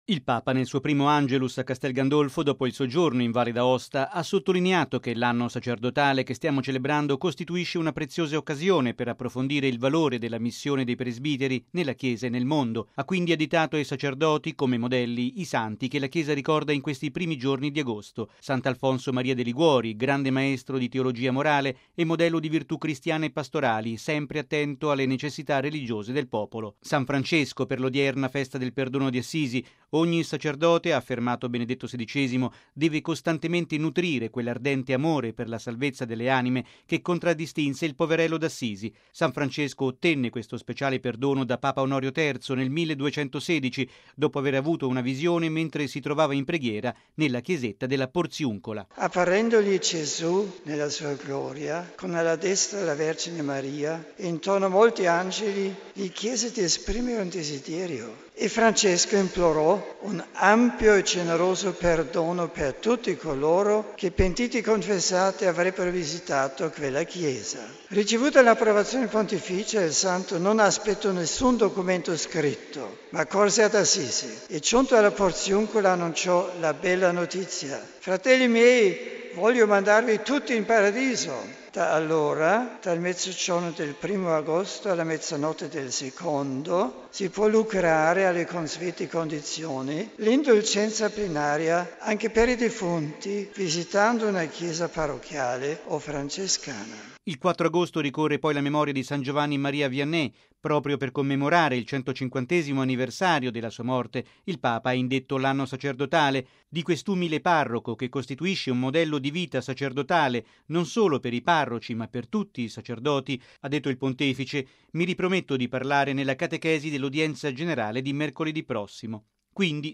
(applausi)